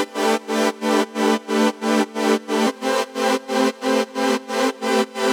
Index of /musicradar/sidechained-samples/90bpm